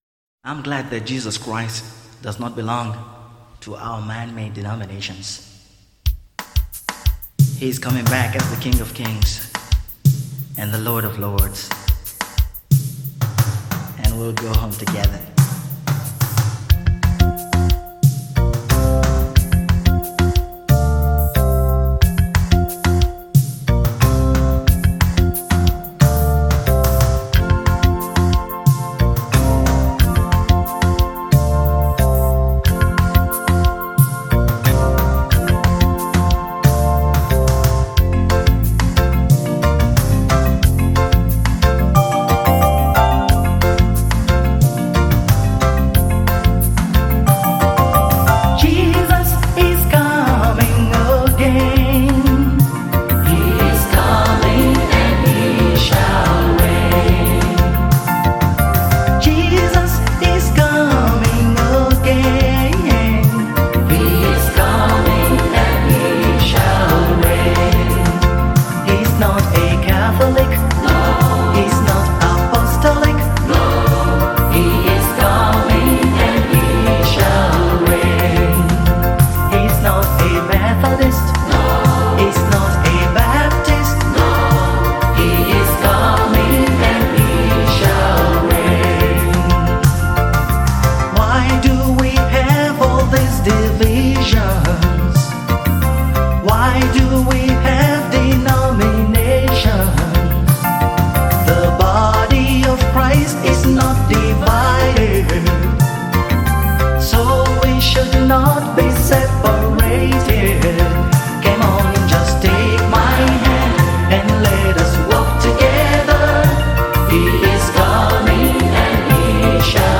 is a Nigerian gospel singer